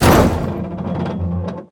car-metal-impact-4.ogg